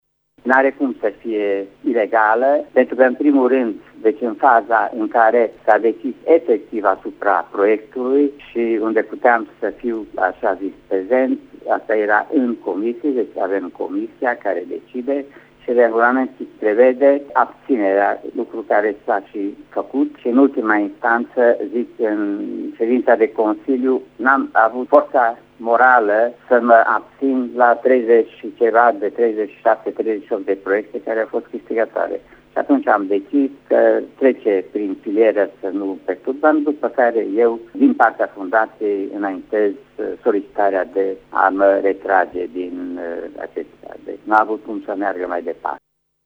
Consilierul Kikeli Pal a declarat pentru Radio Tîrgu Mureș că, din punctul său de vedere, hotărârea este legală deoarece el s-a abţinut de la vot în comisie şi a retras proiectul fundaţiei, acesta nemaiputând beneficia de finanţare din bugetul local: